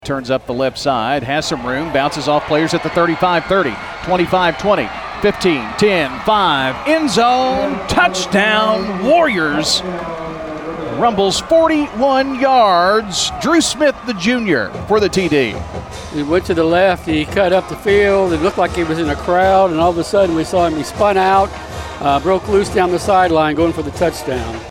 with the call on State Farm Prep Football.